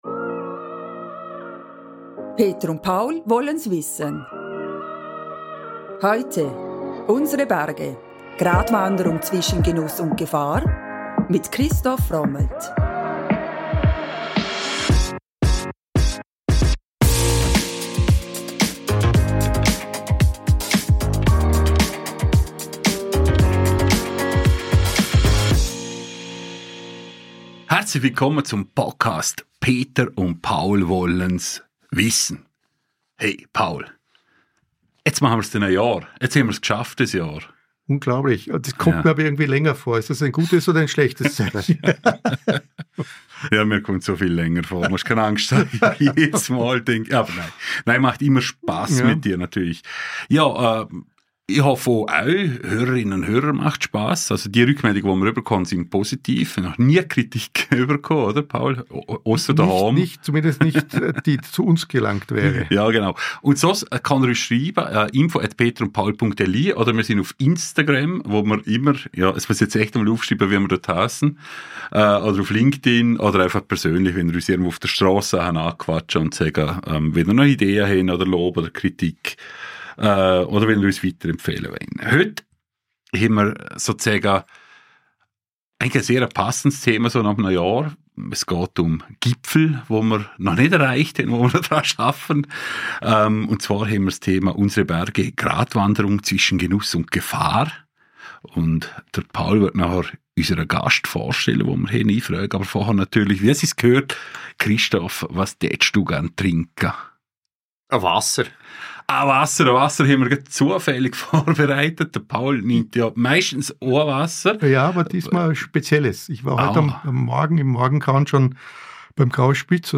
Kurz: Er kennt alle Höhen und Tiefen und spricht mit uns darüber.